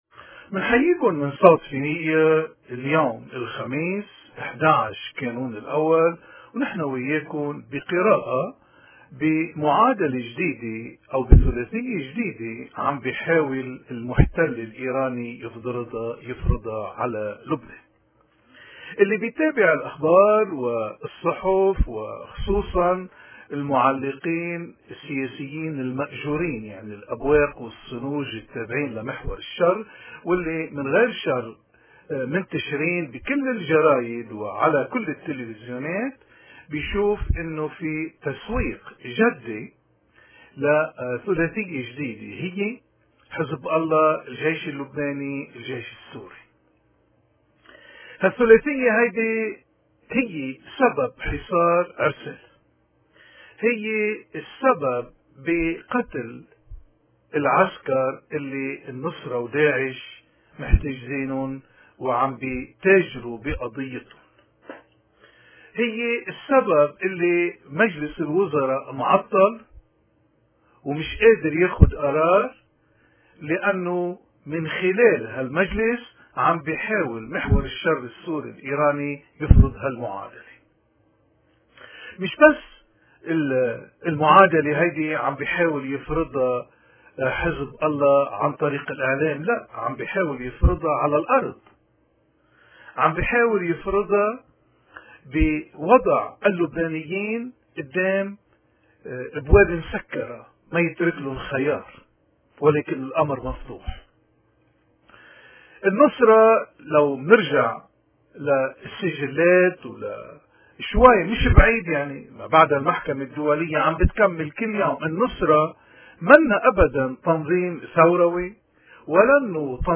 نشرة الأخبار العربية ليوم 12 كانون الأول/2014